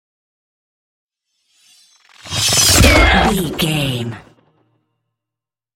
Whoosh metal sword
Sound Effects
whoosh